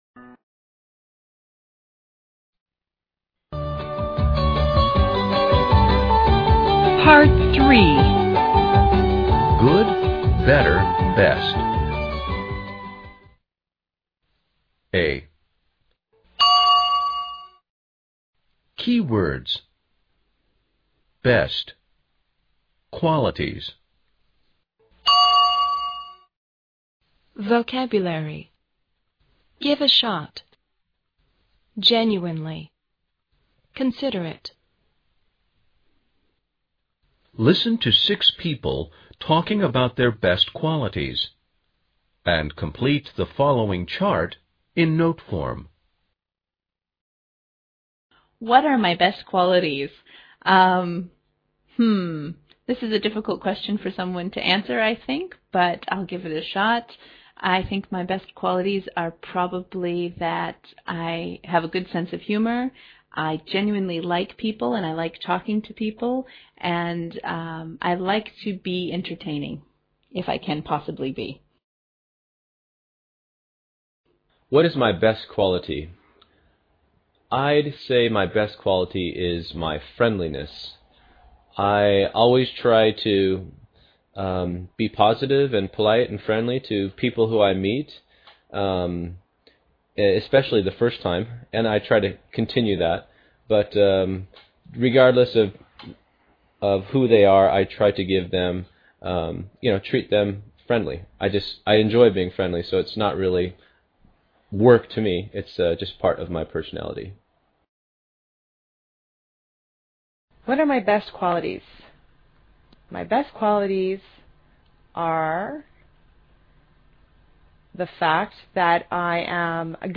Listen to six people talking about their best qualities and complete the following chart in note form.